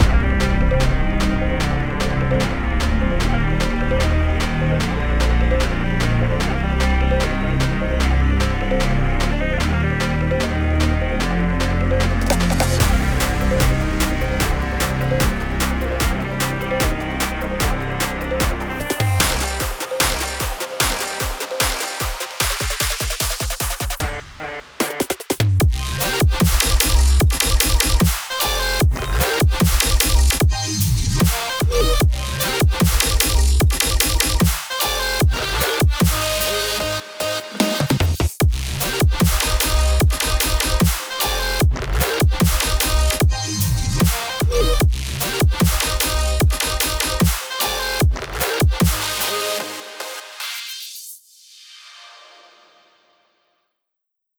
ישבתי על סרום וניסתי ליצור סאונד של גיטרה חשמלית זה נראה לי יצא יפה אבל מלוכלך (וזה יצא יותר מונו מאשר סטריאו נראה לי) אשמח לתגובה על זה מהמומחים האם זה באמת נשמע גיטרה חשמלית?
ונראה לי שאפשר לעשות על זה אתגר שתקחו את הקטע הזה ותתנו לו את הליווי באיזה זאנר שאתם רוצים (טמפו 150 אבל אפשר לשנות)